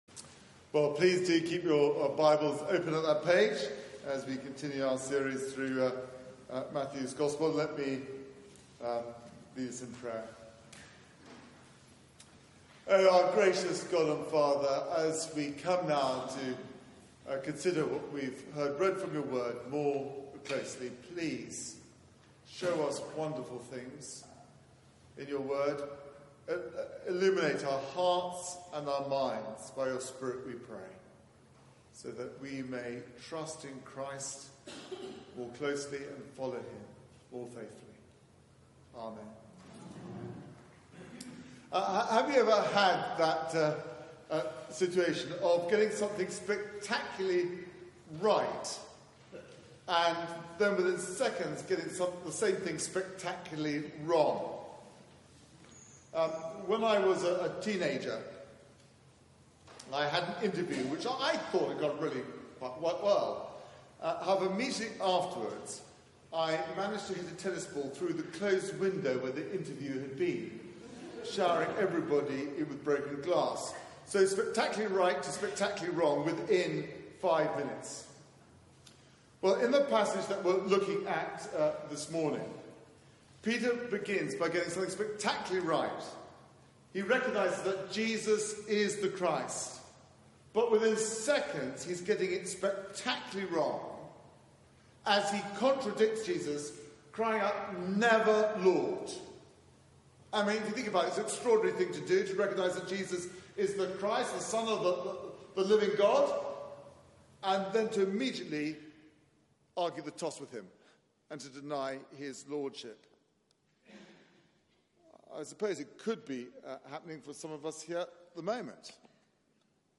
Media for 9:15am Service on Sun 25th Nov 2018
Series: The School of Christ Theme: He must take up his cross Sermon